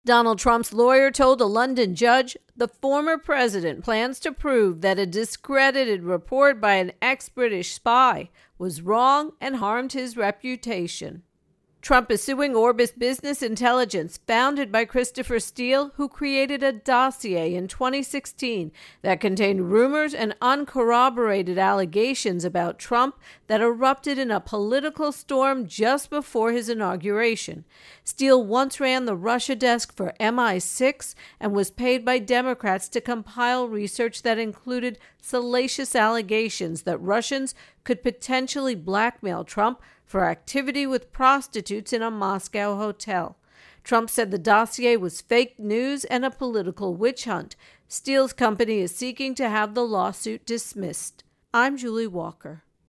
reports on Trump Steele Dossier Lawsuit.